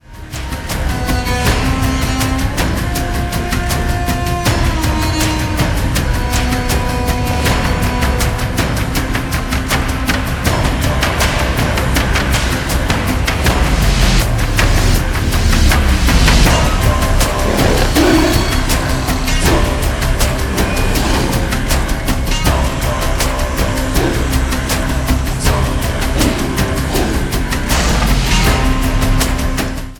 Binaural theta meditation music – duration 09:47 minutes